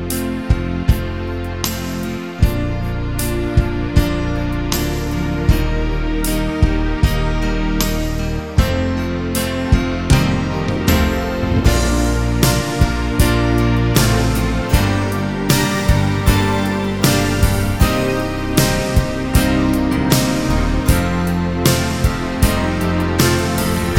Two Semitones Down Pop (1980s) 4:45 Buy £1.50